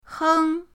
heng1.mp3